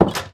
Minecraft Version Minecraft Version latest Latest Release | Latest Snapshot latest / assets / minecraft / sounds / block / wooden_door / close1.ogg Compare With Compare With Latest Release | Latest Snapshot